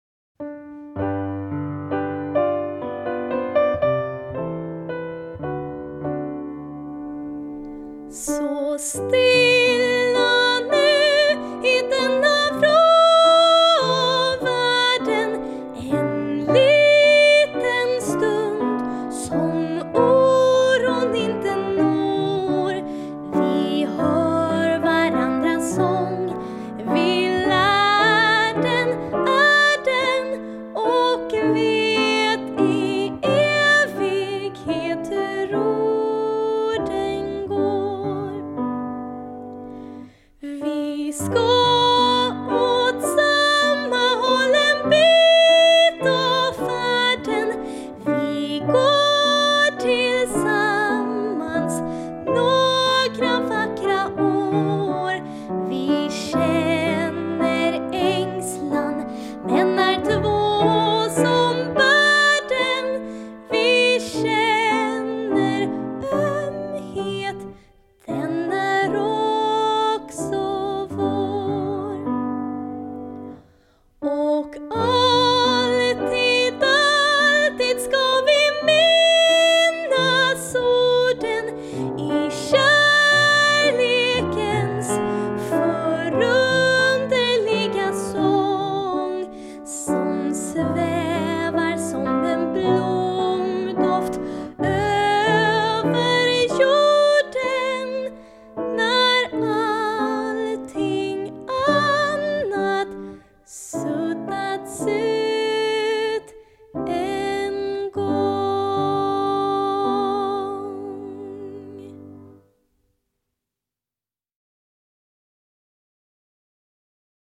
Piano och arr